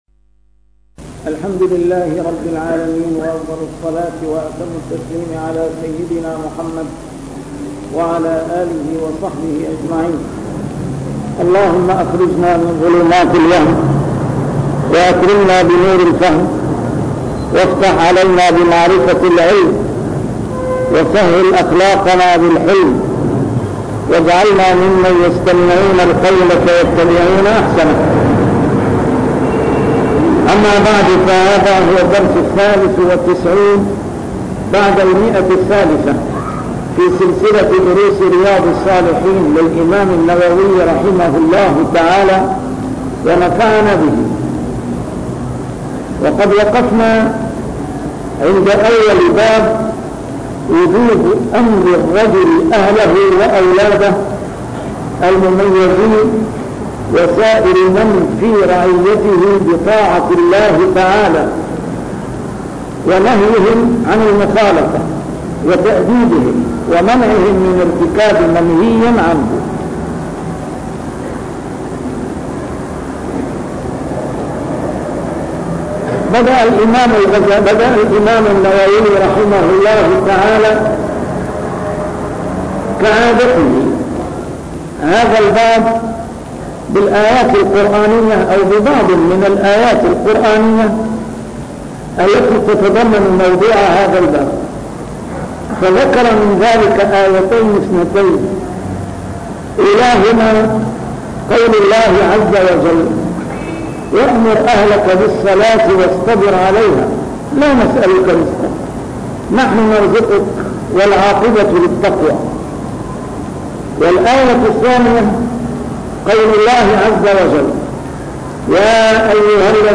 A MARTYR SCHOLAR: IMAM MUHAMMAD SAEED RAMADAN AL-BOUTI - الدروس العلمية - شرح كتاب رياض الصالحين - 393- شرح رياض الصالحين: أمر الرجل أهله بطاعة الله